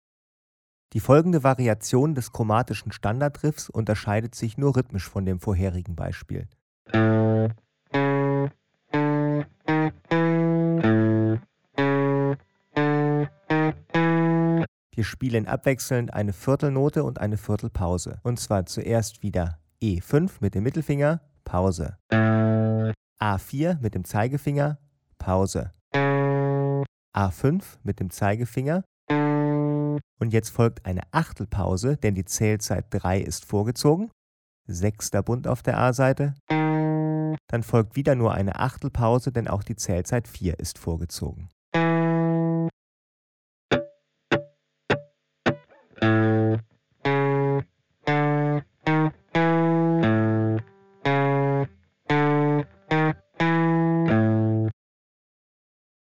Als MODERIERTE MP3-CD mit mehr als vier Stunden Spieldauer bietet sie präzise Anleitungen zu Fingersätzen, rhythmischen Besonderheiten und viele Playbacks zum Mitspielen.
sound_clippingDurchgangstöne